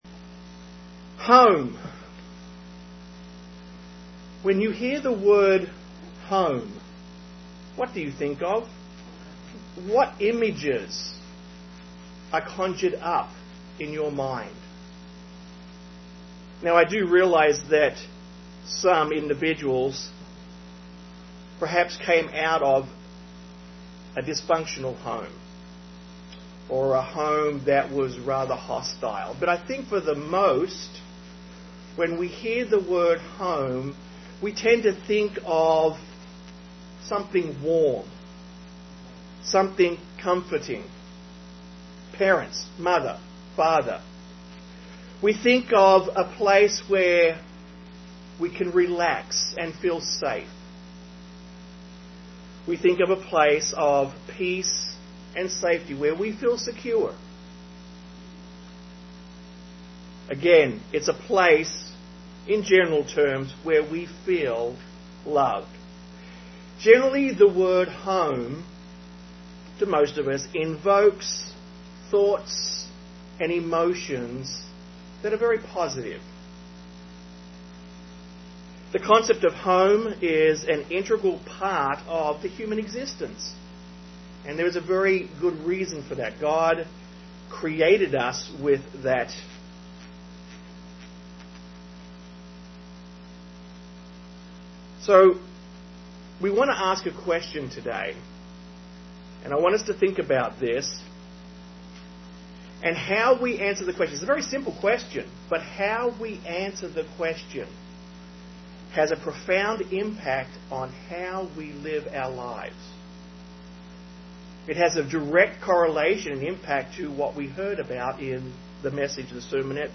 Given in Austin, TX
UCG Sermon Studying the bible?